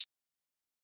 Closed Hats
MZ Hi Hat [Trap_UK Drill].wav